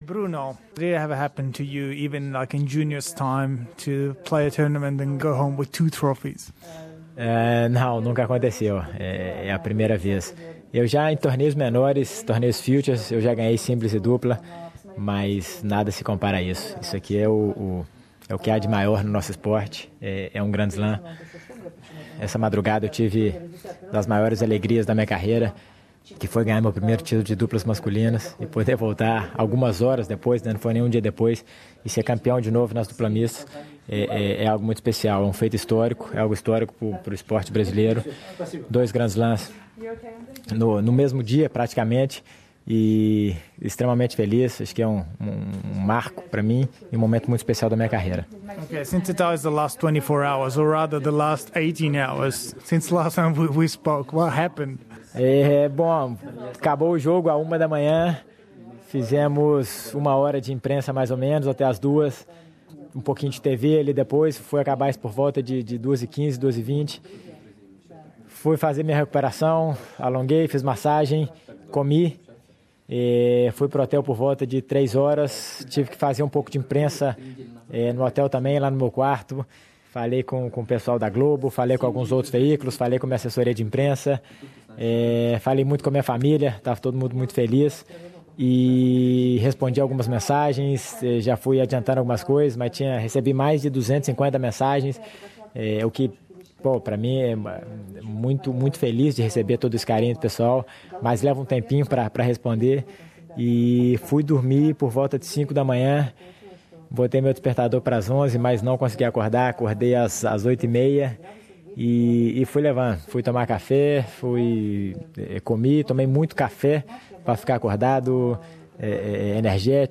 O tenista brasileiro, autor do extraordinário feito de conquistar duas vezes o título de campeão em um mesmo Grand Slam, fala nesta entrevista